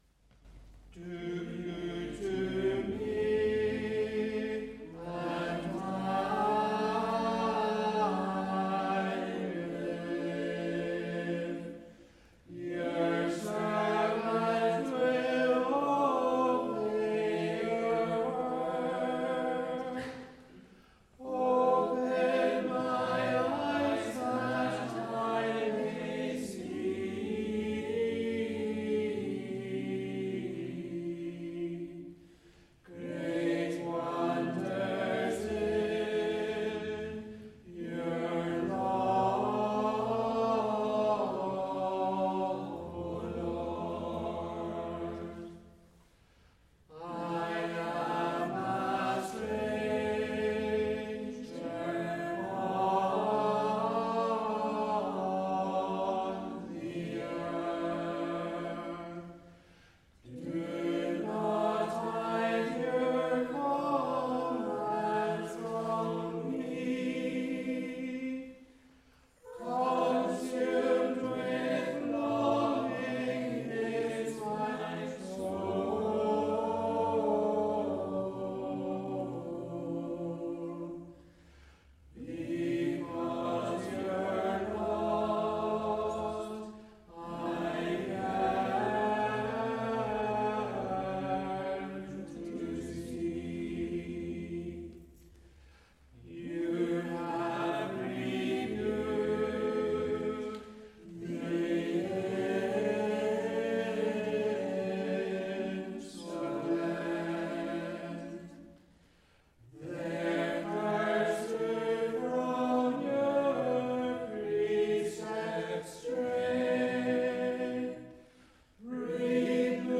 Congregational Psalm Singing